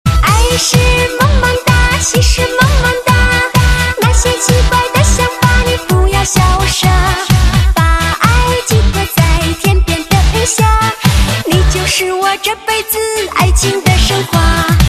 M4R铃声, MP3铃声, 华语歌曲 63 首发日期：2018-05-15 02:59 星期二